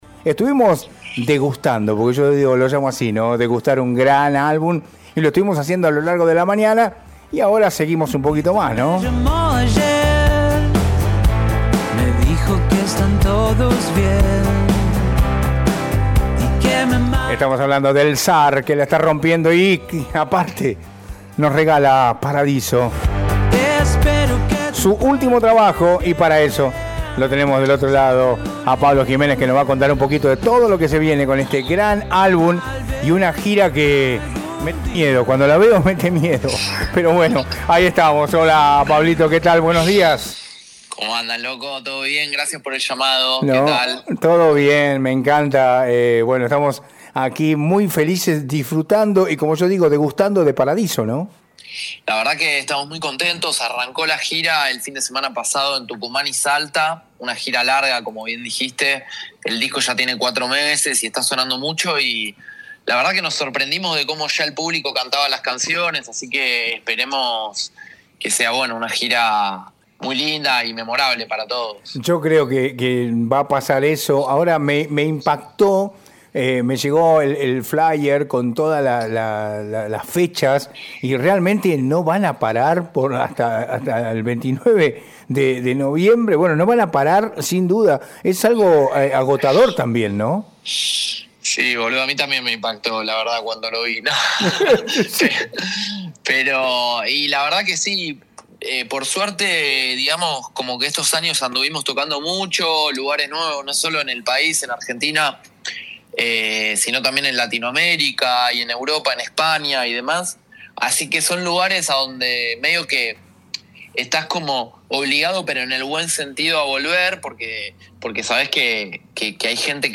en Acá Rock en Radio Cristal 94,9